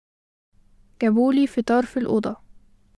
[ gabuu-l-i feTaar fe-l-ooDa. ]